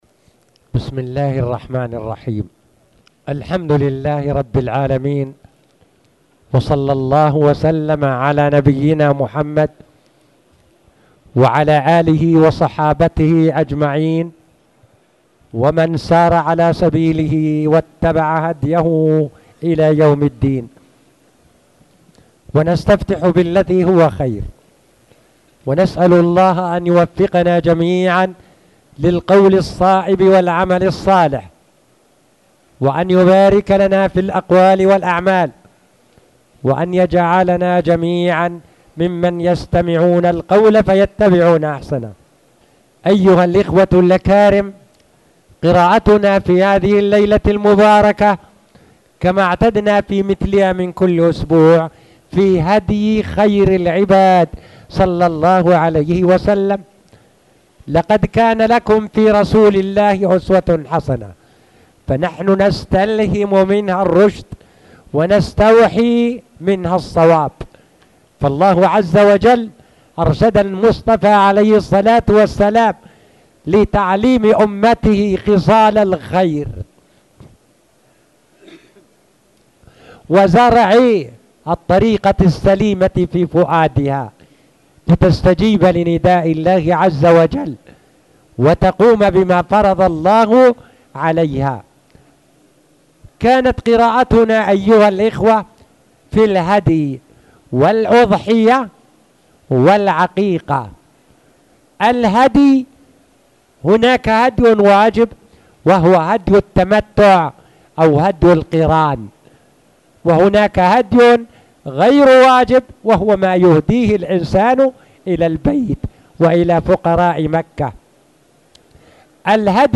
تاريخ النشر ٣٠ ربيع الأول ١٤٣٨ هـ المكان: المسجد الحرام الشيخ